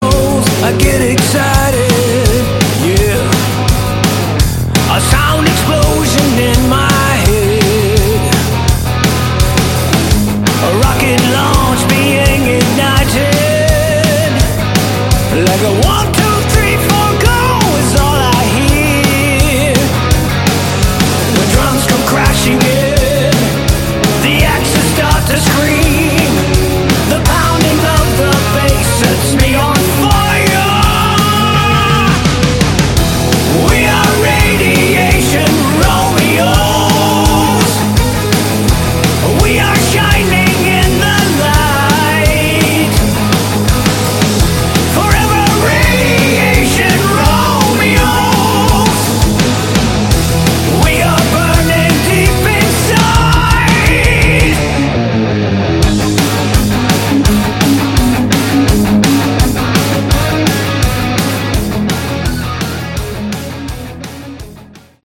Category: Melodic Rock
bass
vocals
guitars
drums
Catchy choruses, Good Melodic HardRock. 80/100